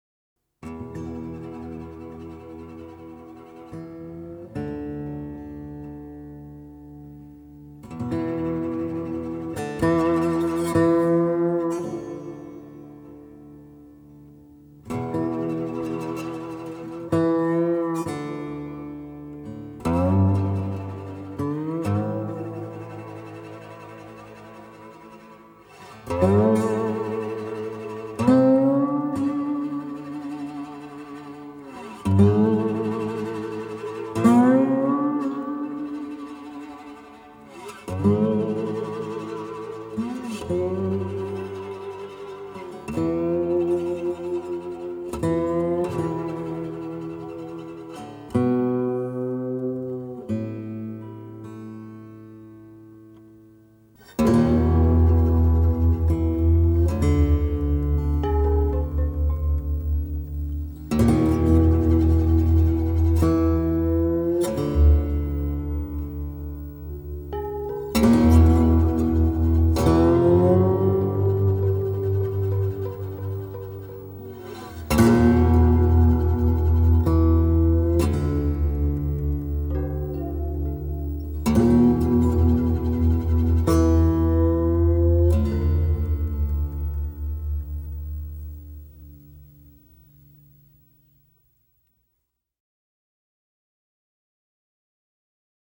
每每听到那空灵、孤独的滑棒吉他弦音，听者的思绪会飘到美国西部辽阔而荒芜的沙漠，最后消失在低垂的夜幕中。